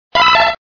Cri d'Hypocéan dans Pokémon Diamant et Perle.